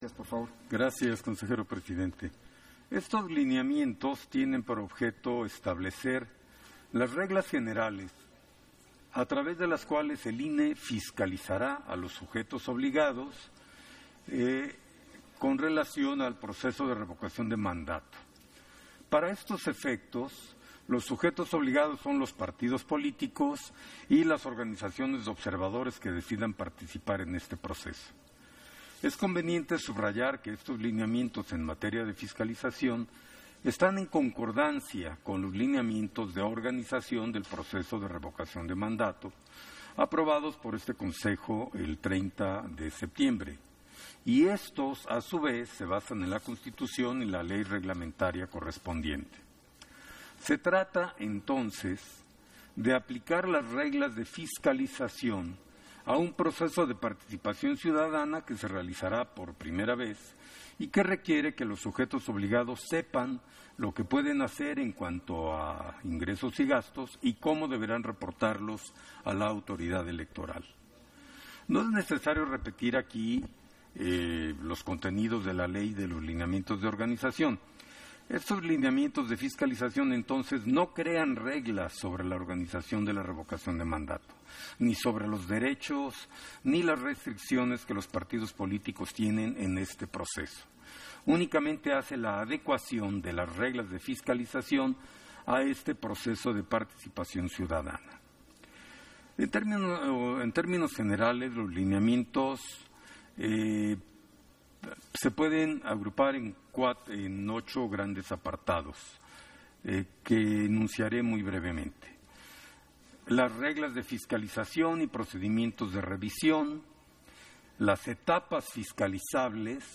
Intervención de Jaime Rivera, en Sesión Extraordinaria, en el punto en que se aprueban los lineamiento de fiscalización del proceso de Revocación de Mandato e informes de ingresos y gastos que se presenten